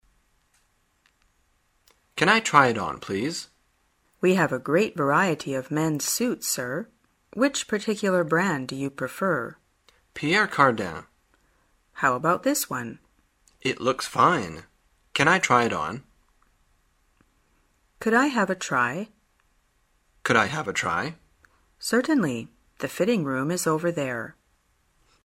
旅游口语情景对话 第251天:如何说明想要试穿衣服